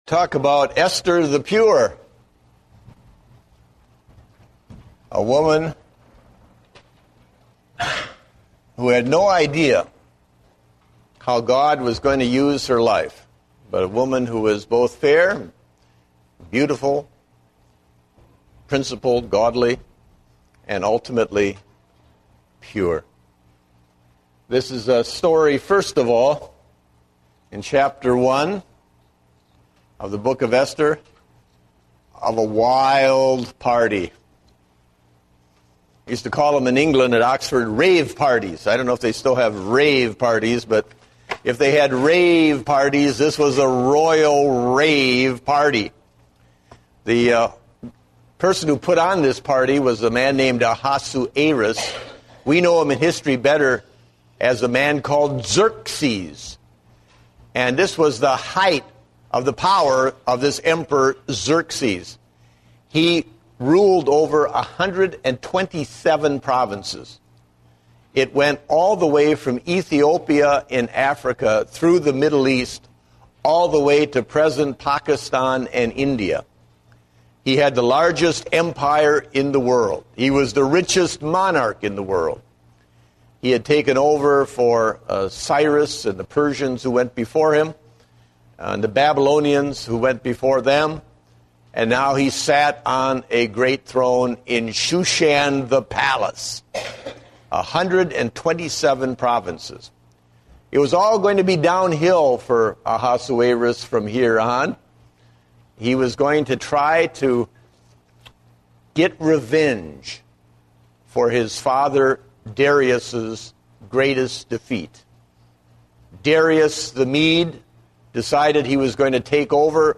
Date: June 6, 2010 (Adult Sunday School)